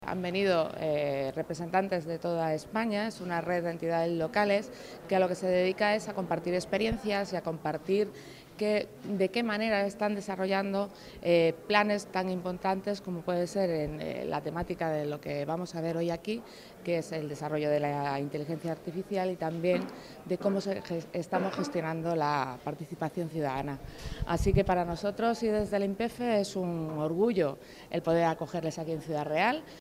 El alcalde de Ciudad Real, Francisco Cañizares, y la concejal de Promoción Económica, Yolanda Torres, han asistido a la inauguración del evento celebrada en el Museo del Quijote.
inauguracion_del_encuentro_redel-yolanda_torres.mp3